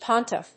音節pon・tiff 発音記号・読み方
/pάnṭɪf(米国英語), pˈɔnṭɪf(英国英語)/